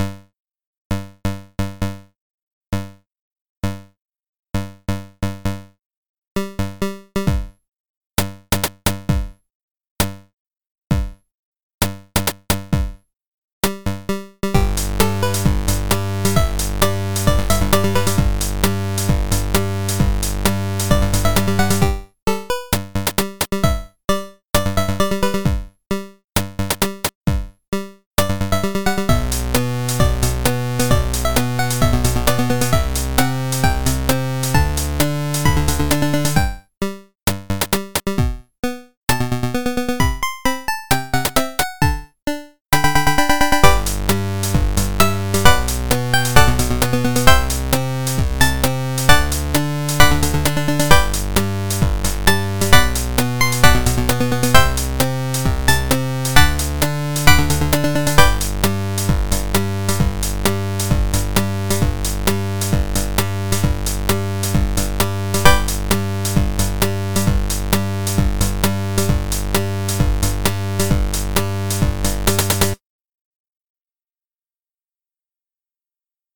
Hi! Looking for 8-bit music?